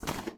piedra_papel_lanzar.wav